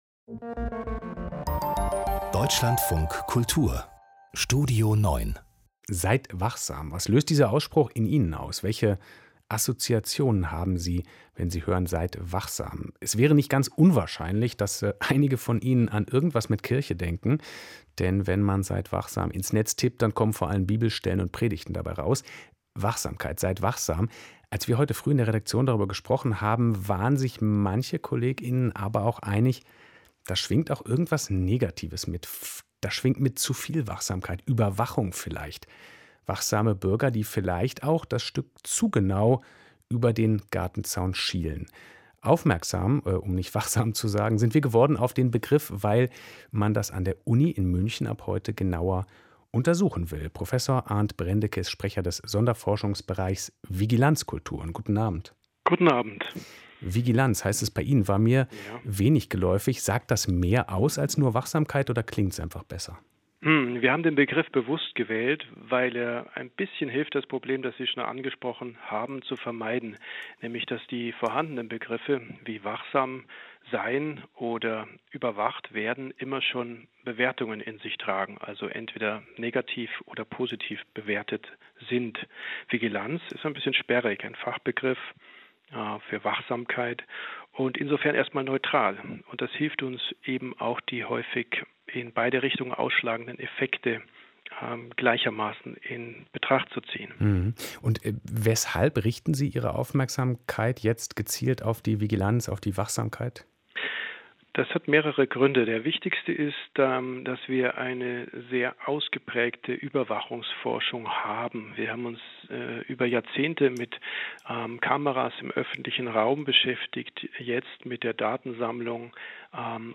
interview-vigilanzkulturen.mp3